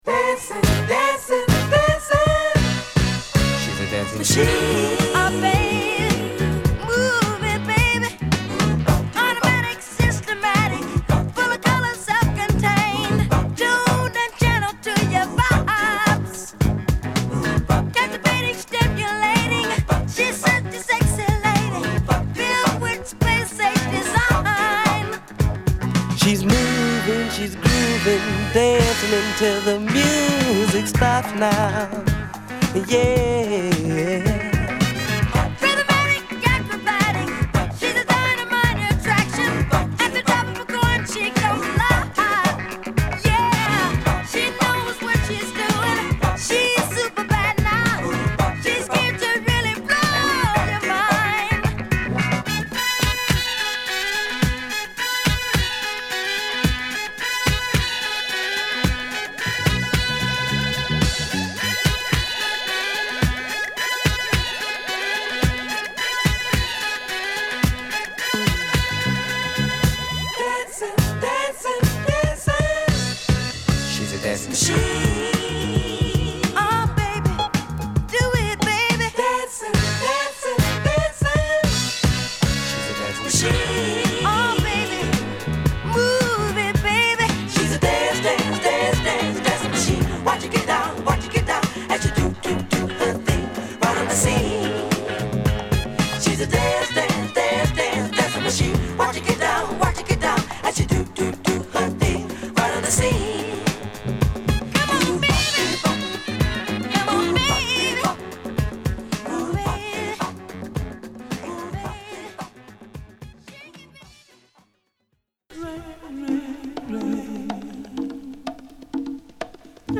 ナイスダンスチューンを満載！